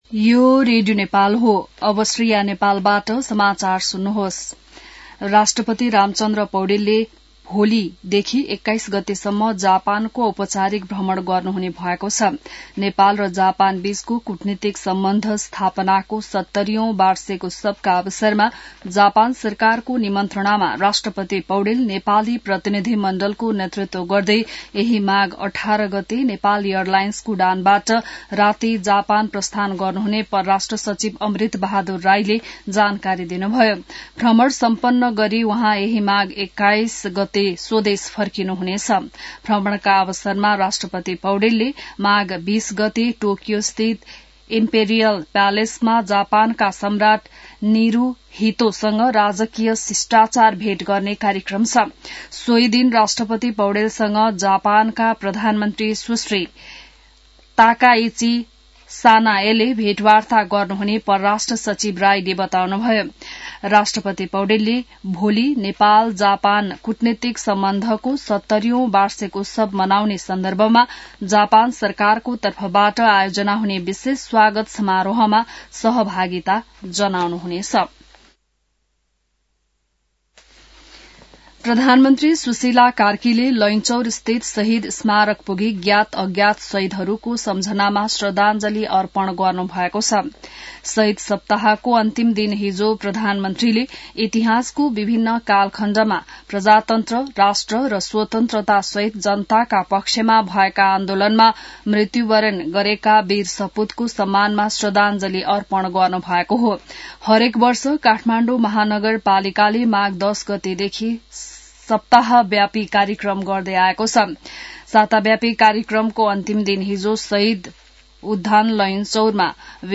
An online outlet of Nepal's national radio broadcaster
बिहान ६ बजेको नेपाली समाचार : १७ माघ , २०८२